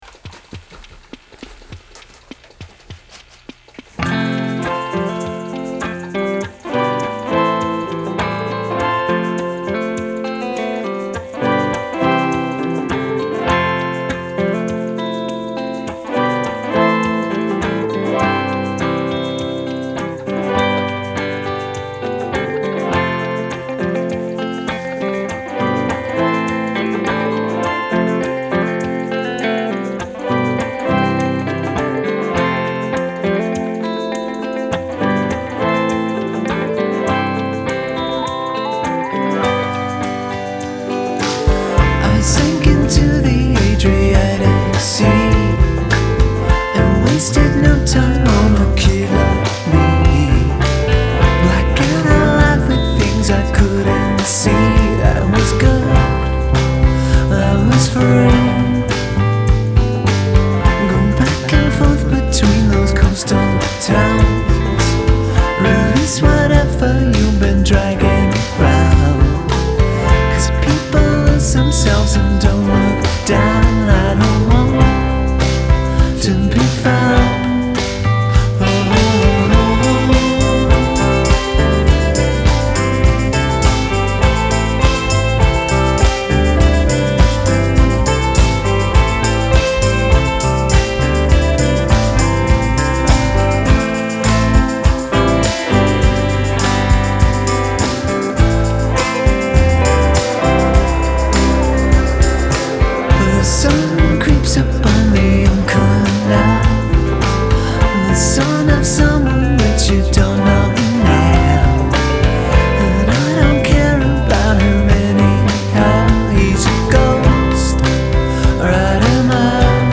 guitars
keys
bass
drums